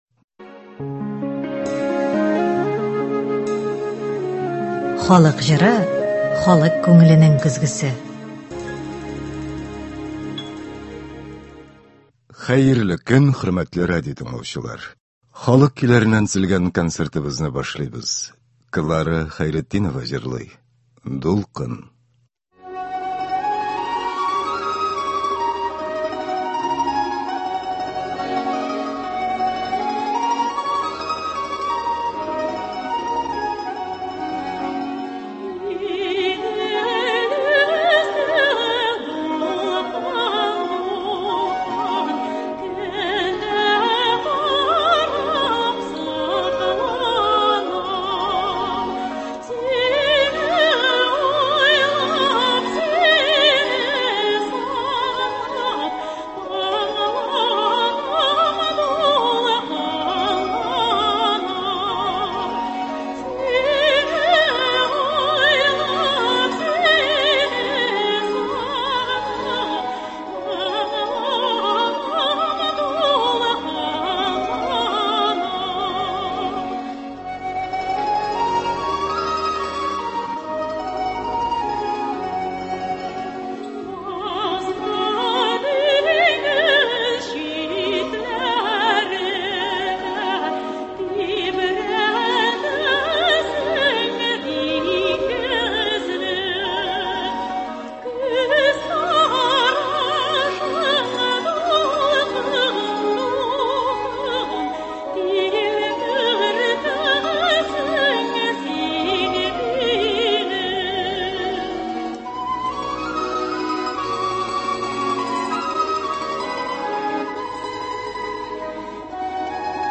Татар халык көйләре (12.08.23)
Бүген без сезнең игътибарга радио фондында сакланган җырлардан төзелгән концерт тыңларга тәкъдим итәбез.